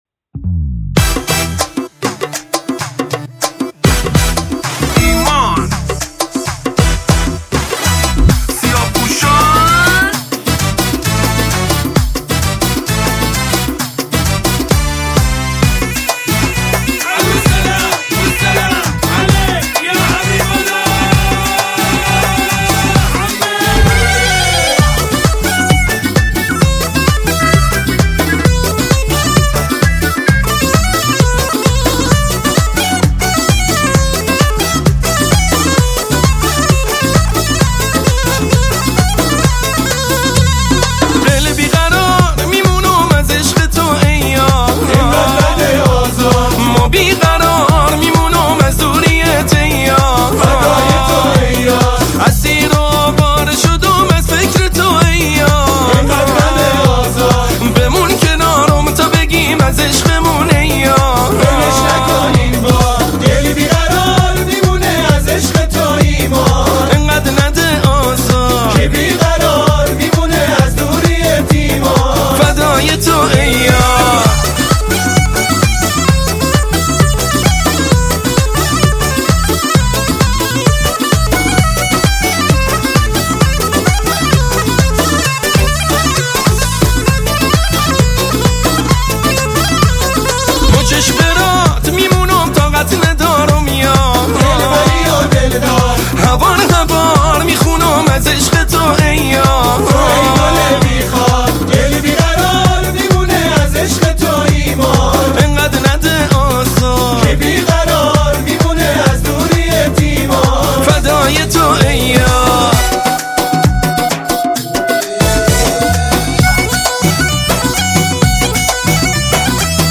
یک اهنگ شاد بندری
یک آهنگ شاد بندری